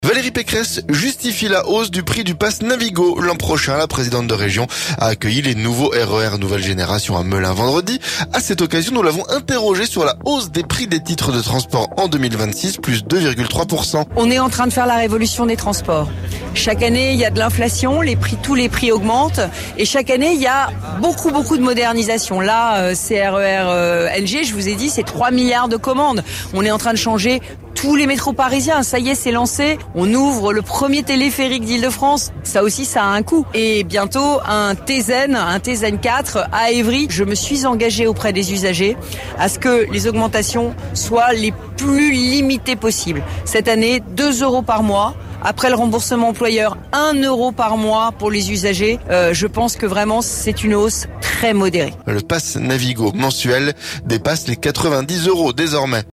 Valérie Pécresse justifie la hausse du prix du Pass Navigo l'an prochain. La Présidente de région a accueilli les nouveaux RER Nouvelle génération à Melun vendredi. A cette occasion, nous l'avons interrogé sur la hausse des prix des titres de transports en 2026, +2,3%.